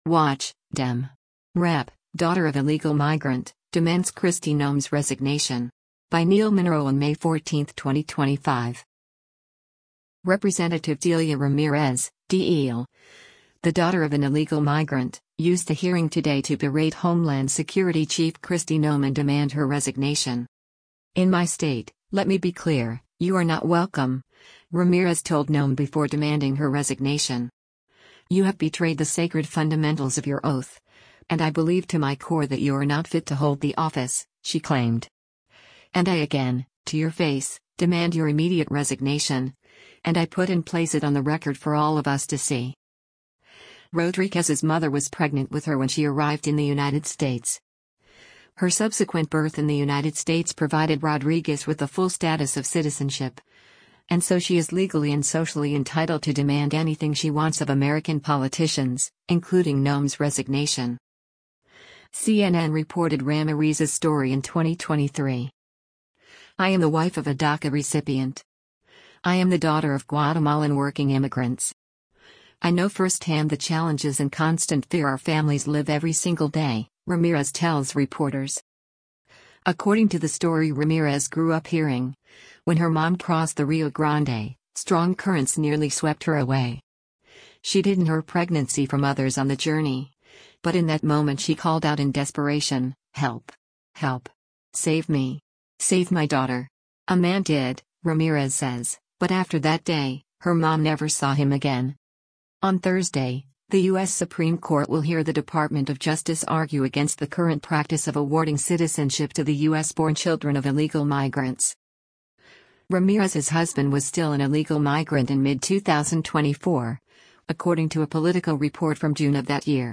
Rep. Delia Ramirez (D-IL), the daughter of an illegal migrant, used a hearing today to berate Homeland Security chief Kristi Noem and demand her resignation.
In the hearing Wednesday of the House Homeland Security Committee, Ramirez asked Noem, “When you took your oath, did you swear to support and uphold the Constitution of the United States of America? ”